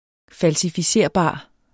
Udtale [ falsifiˈseɐ̯ˀˌbɑˀ ]